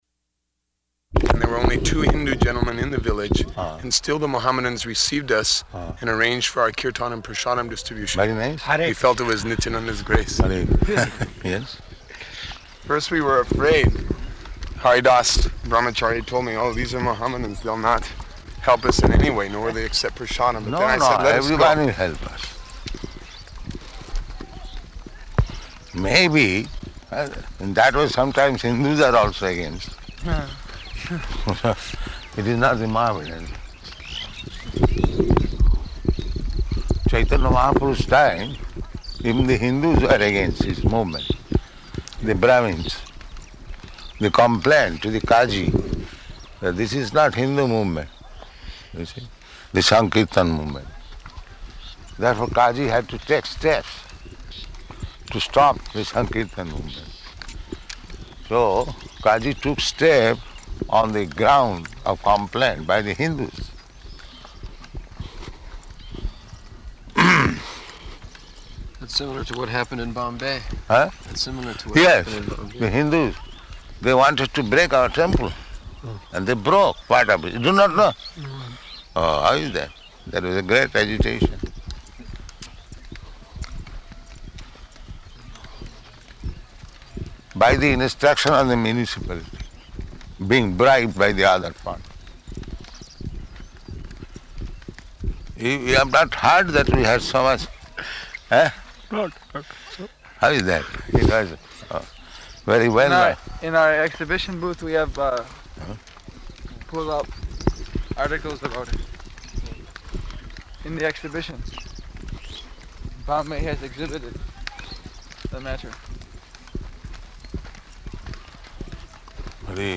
Type: Walk
Location: Māyāpur